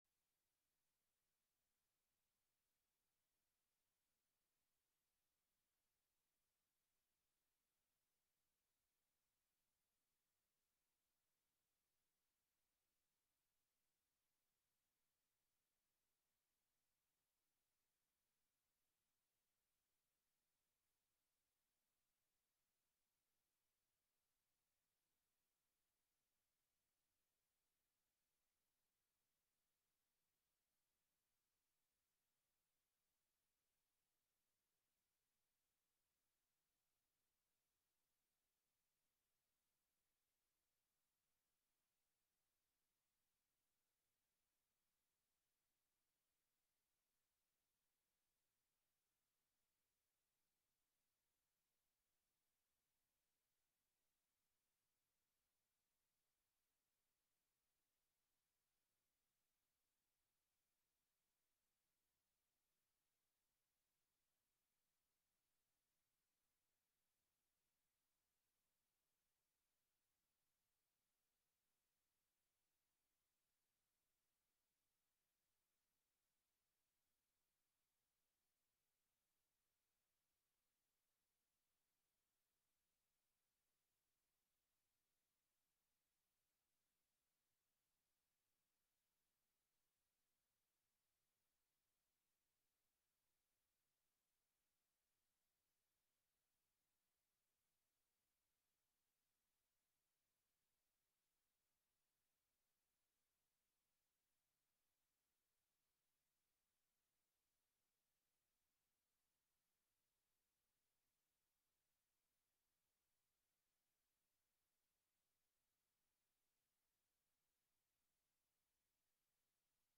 2026年3月29日溫城華人宣道會粵語堂主日崇拜